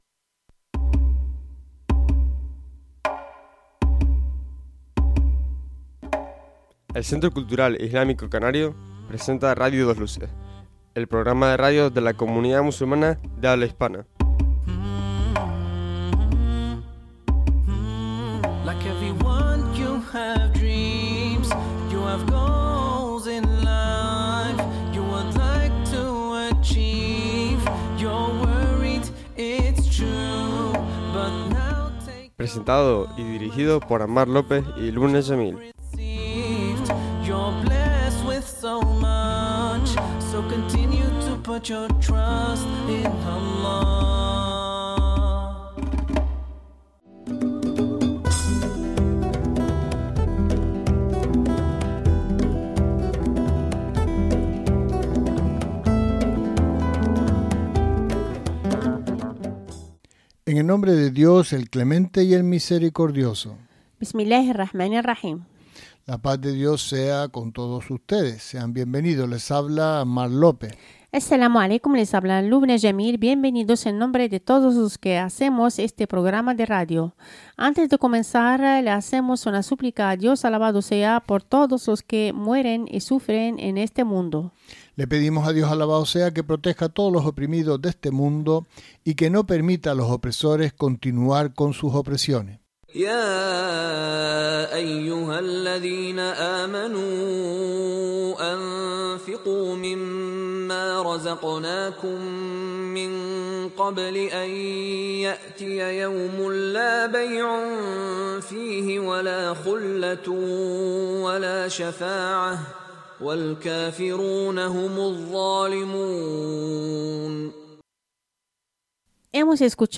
El programa completo en Radio 2 Luces. Con todas las secciones de actualidad, debate, entrevistas, música,...